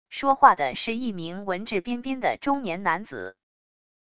说话的是一名文质彬彬的中年男子wav音频